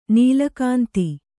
♪ nīla kānti